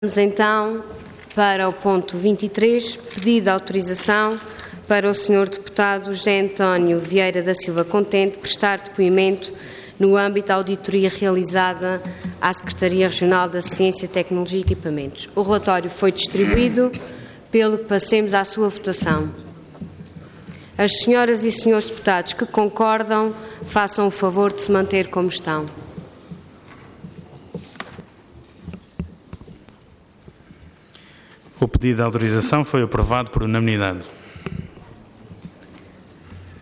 Assembleia Legislativa da Região Autónoma dos Açores
Intervenção
Presidente da Assembleia Regional